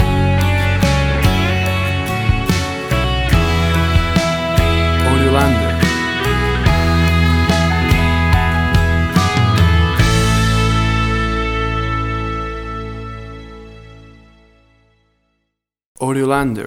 WAV Sample Rate: 16-Bit stereo, 44.1 kHz
Tempo (BPM): 72